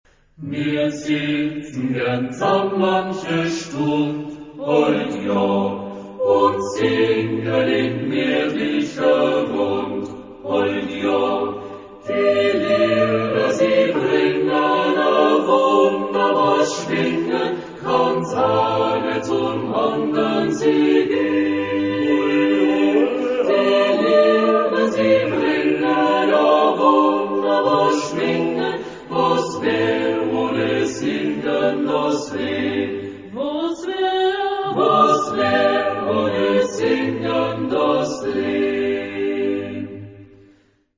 Genre-Style-Form: Secular ; Popular
Mood of the piece: rousing
Type of Choir: SAAB OR SATB  (4 mixed voices )
Tonality: G major